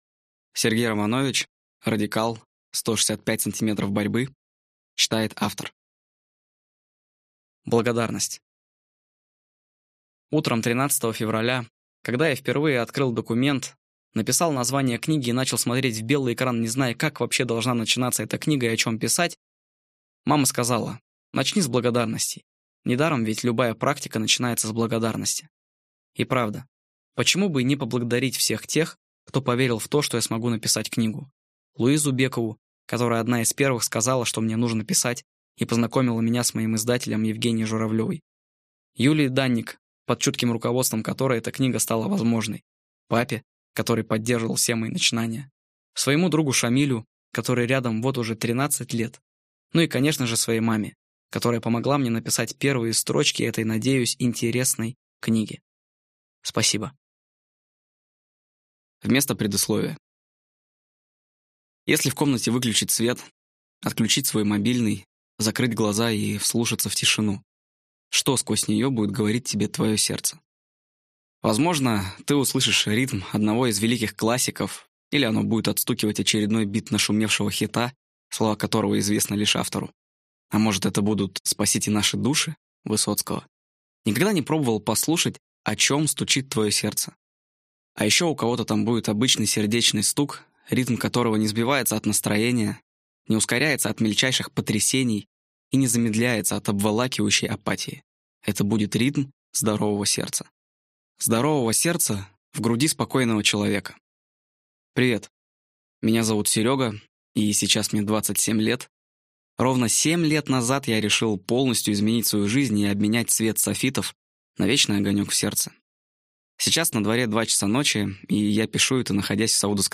Аудиокнига Радикал. 165 сантиметров борьбы | Библиотека аудиокниг